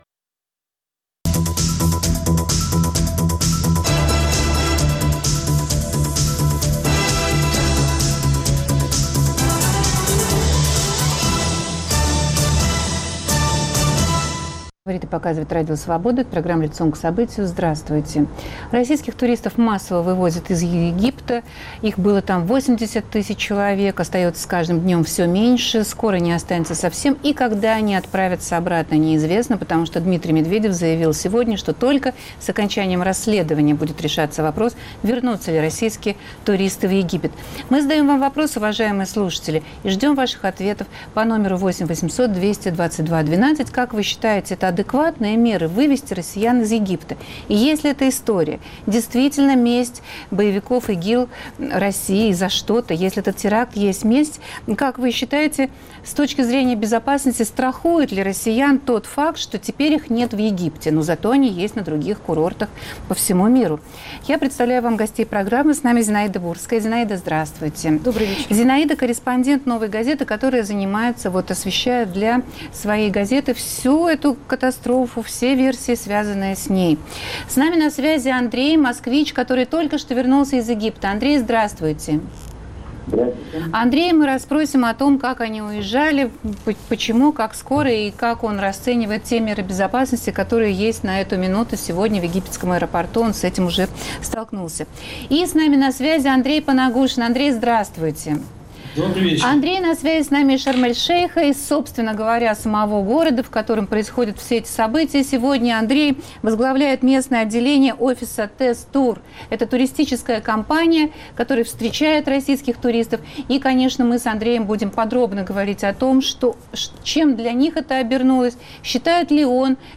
С участием туристов, вернувшихся из Егпита.